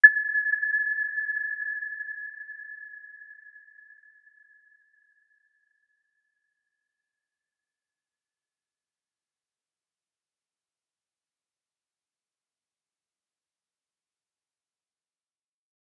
Aurora-B6-mf.wav